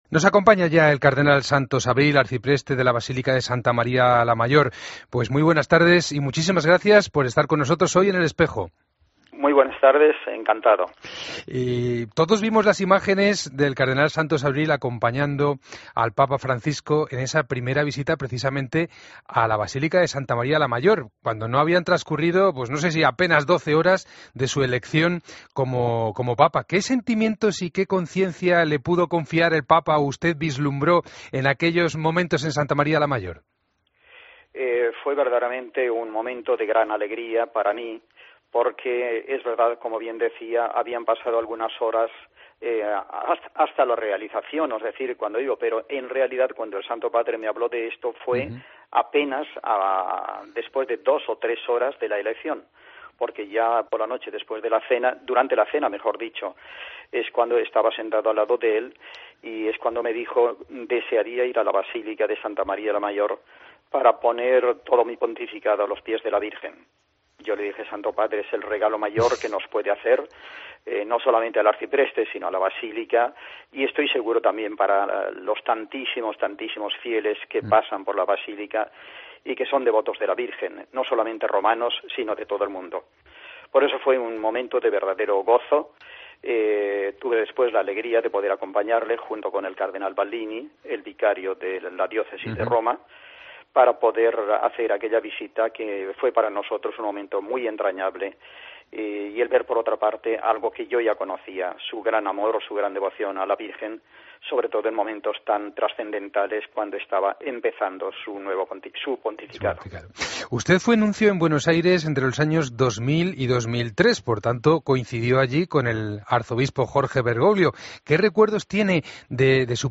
AUDIO: Escucha la entrevista al cardenal Santos Abril en El Espejo